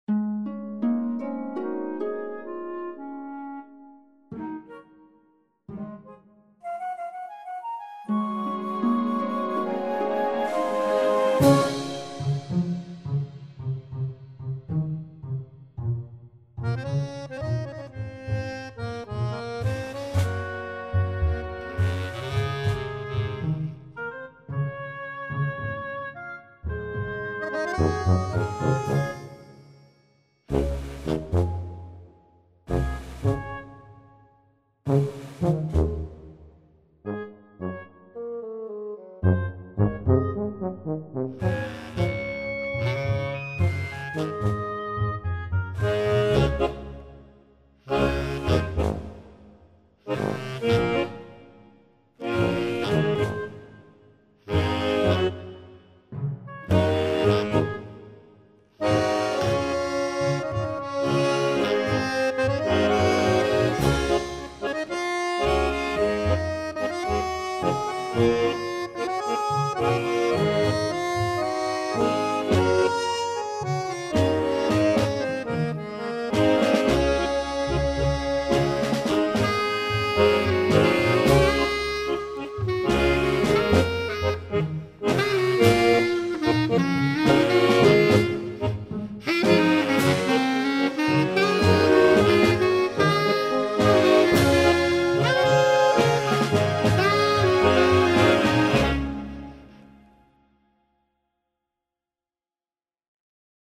tango treatment